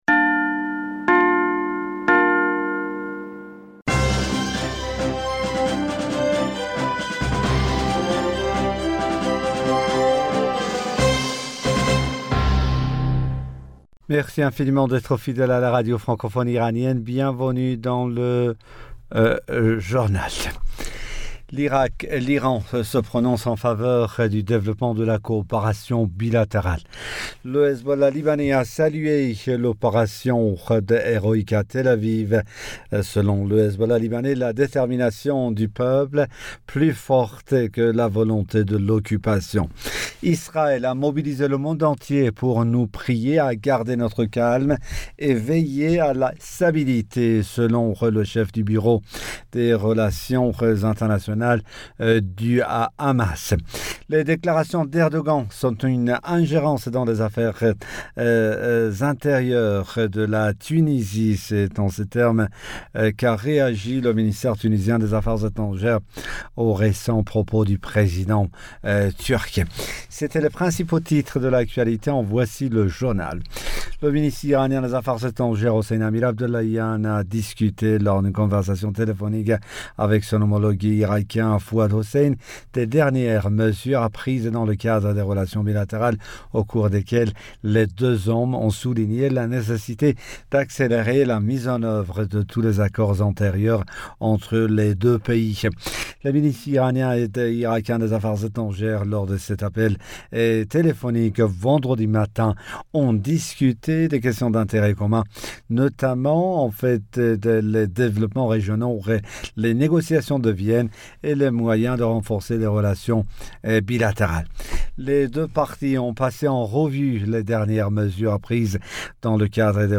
Bulletin d'information Du 08 Avril 2022